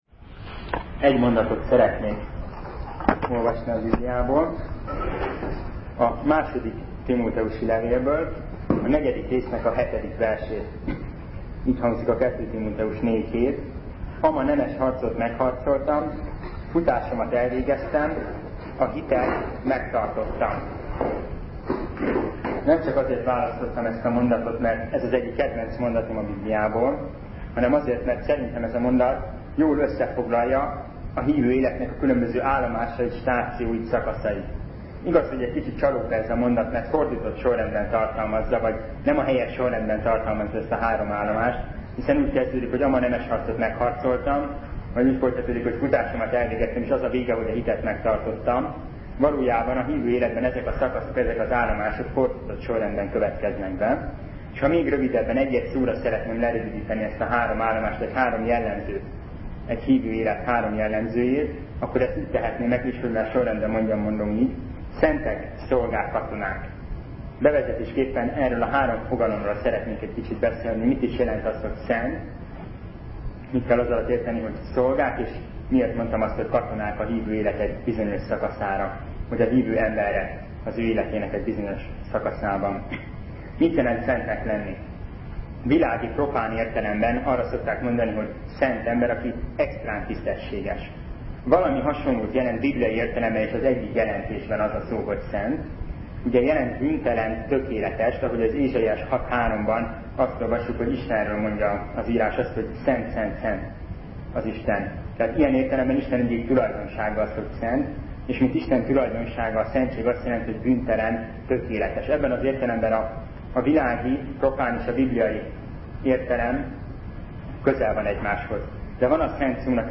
Szentek, szolgák, katonák - bizonyságtétel